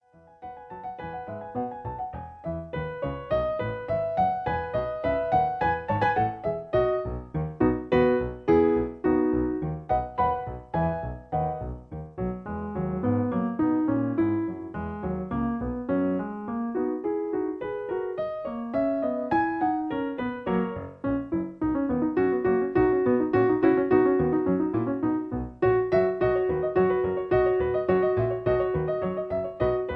In E. Piano Accompaniment